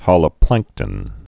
(hŏlə-plăngktən, hōlə-)